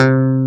CLV_ClavDAC_4 2a.wav